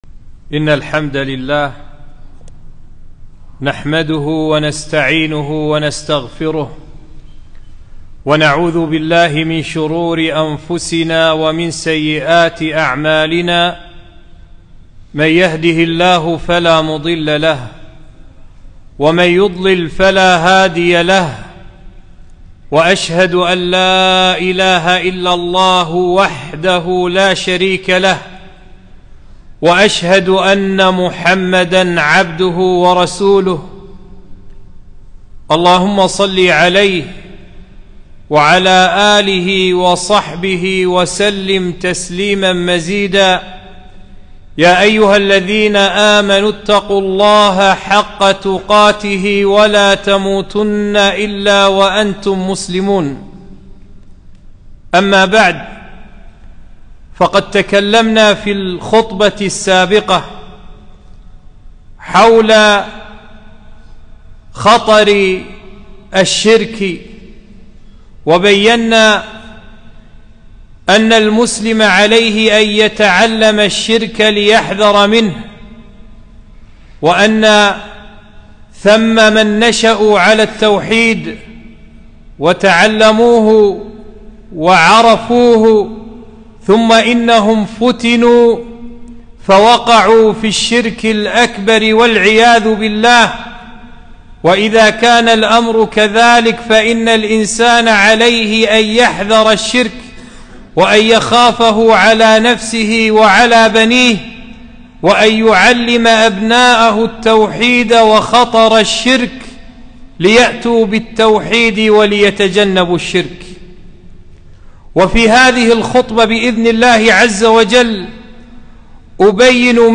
خطبة - الشرك أعظم ذنب عصي الله به